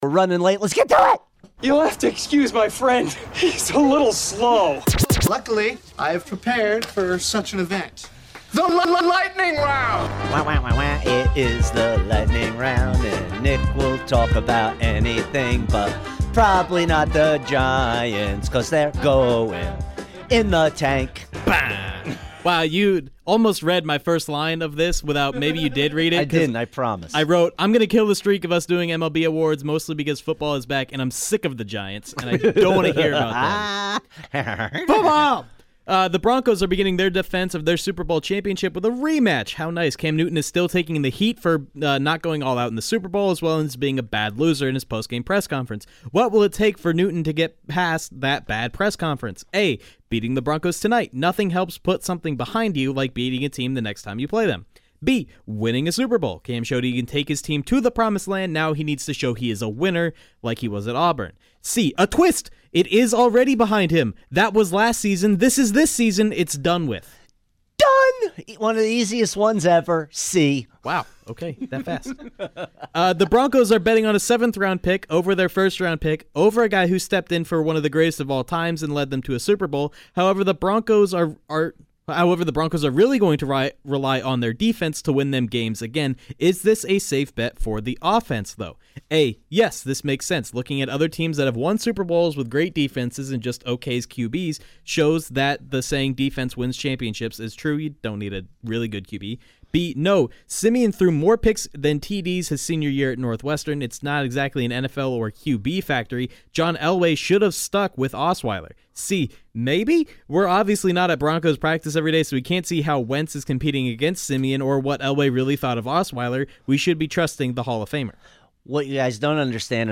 goes rapid fire through the day’s biggest stories